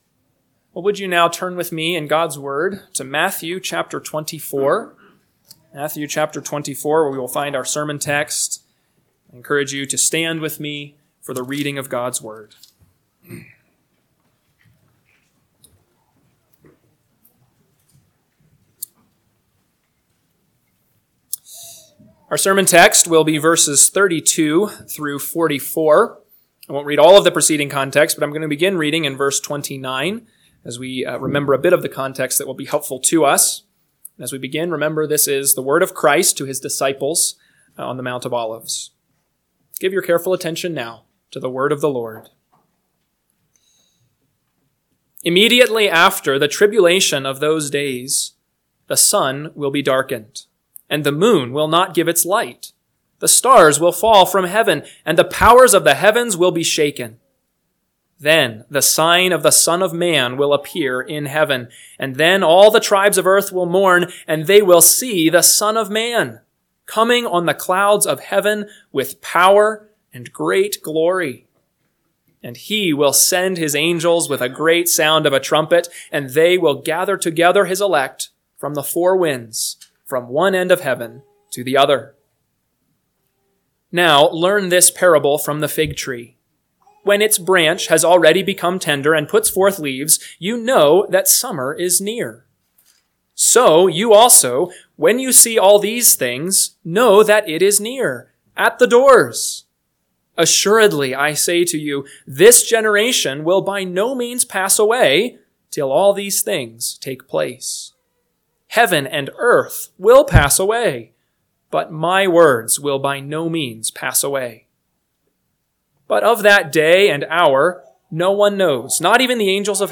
AM Sermon – 1/5/2025 – Matthew 24:32-44 – Northwoods Sermons